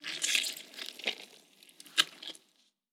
Blood_10.wav